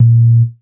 snr_20.wav